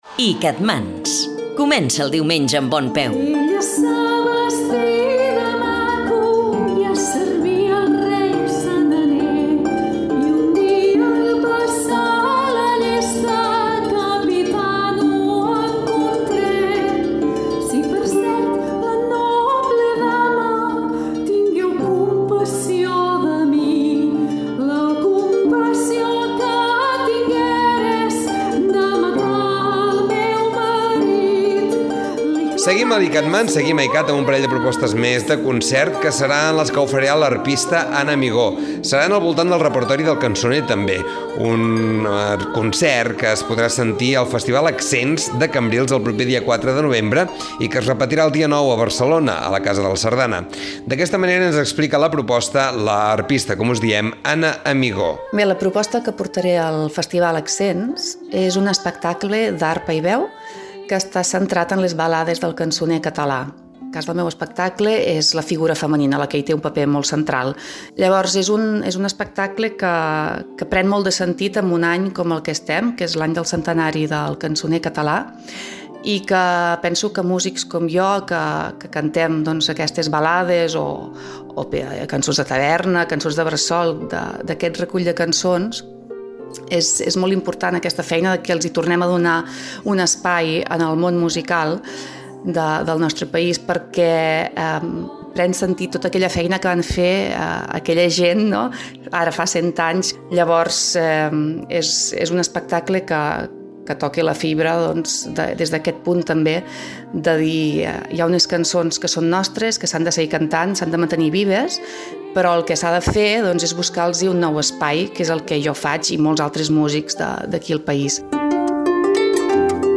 "El cançoner R-evolucionat" - Entrevista iCatMans - Catalunya Ràdio